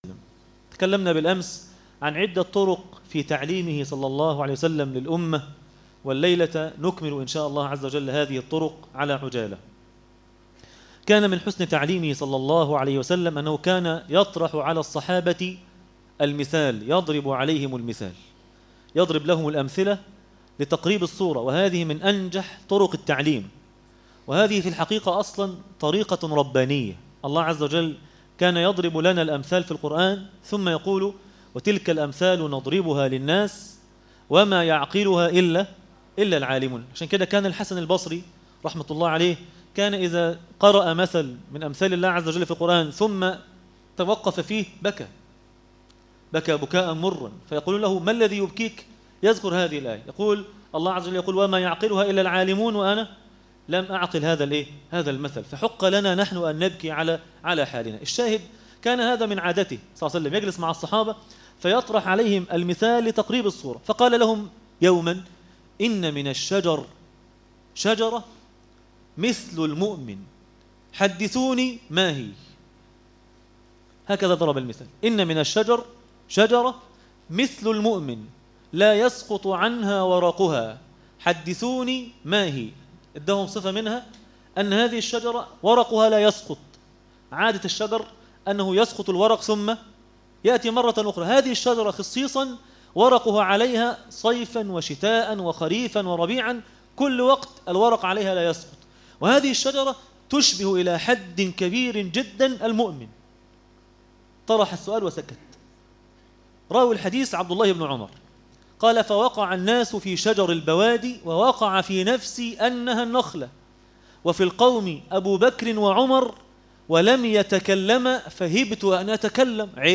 النبي (صلي الله عليه وسلم ) معلما - الجزء الثالث- درس التراويح ليلة 11 رمضان1437هـ